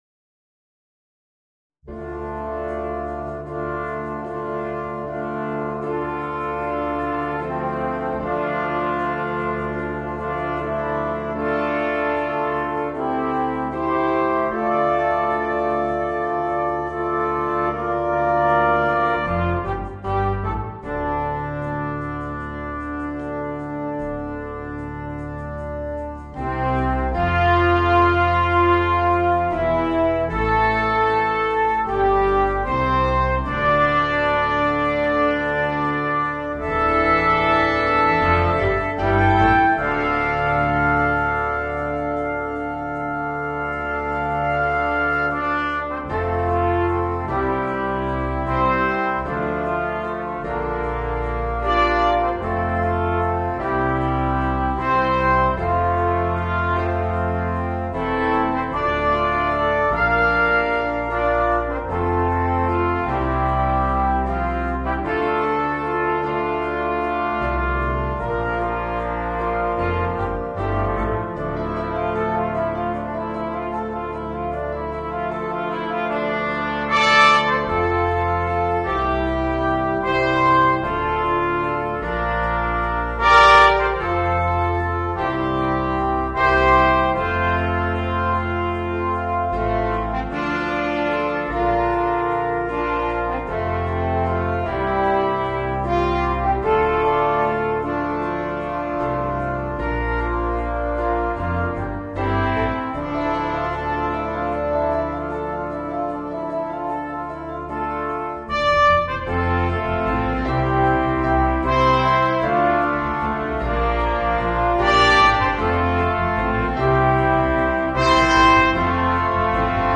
Voicing: Small Ensembles